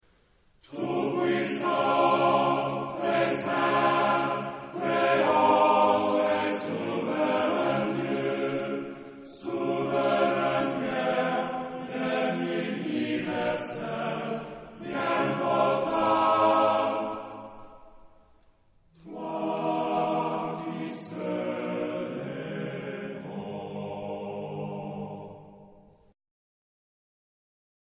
Genre-Style-Forme : Sacré
Caractère de la pièce : majestueux ; élogieux
Type de choeur : TBarBarB  (4 voix égales d'hommes )
Tonalité : ré bémol majeur